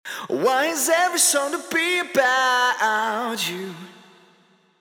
Vocal (FxChain - Vocal Chain 1)
Vocal-FxChain-Vocal-Chain-1.mp3